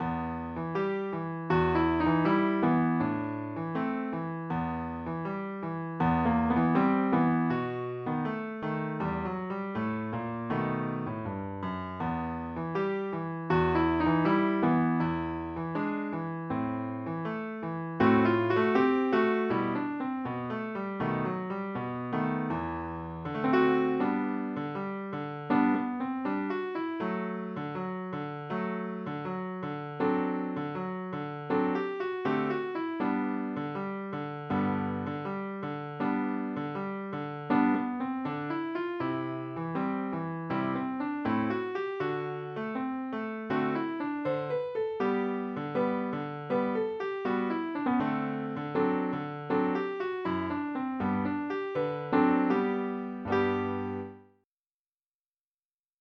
Habanera ***